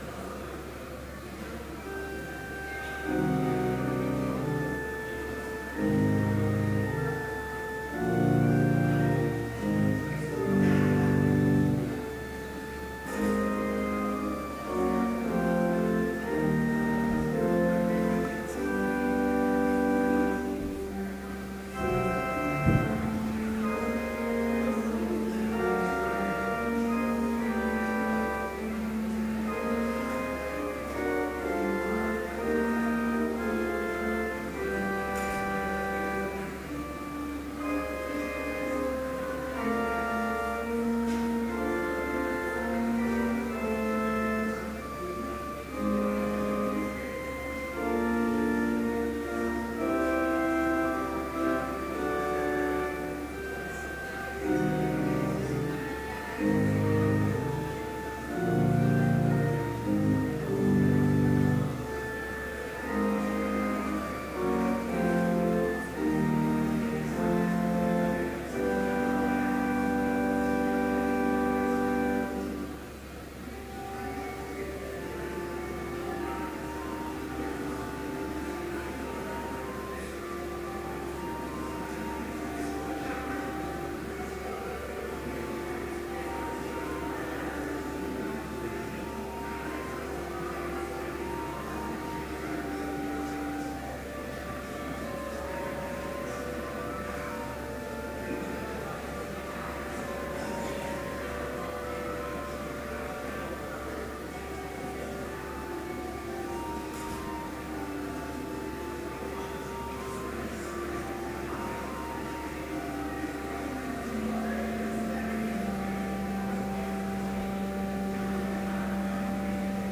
Complete service audio for Chapel - March 18, 2013